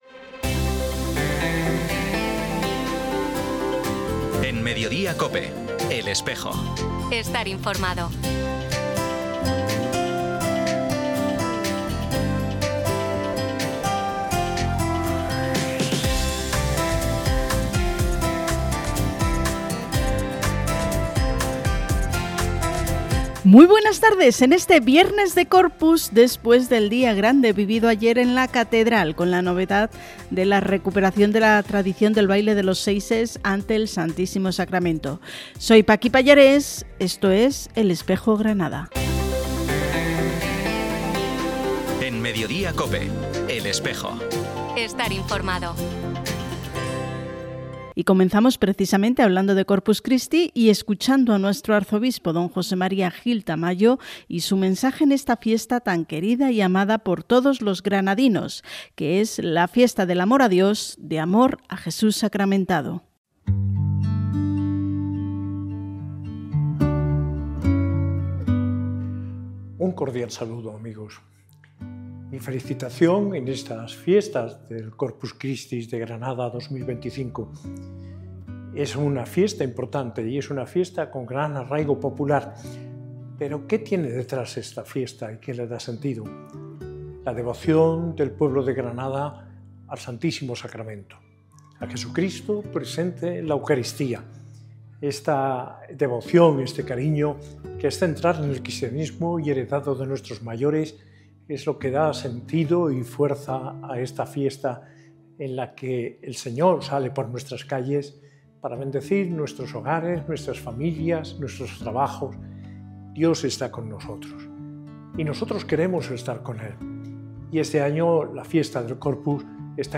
Programa emitido hoy viernes 20 de junio, en COPE Granada y COPE Motril.